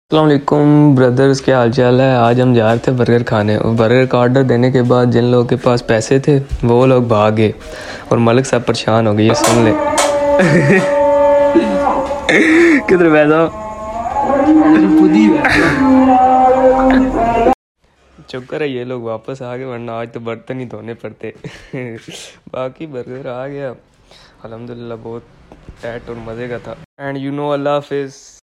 Voice over kesi hei kuch sound effects free download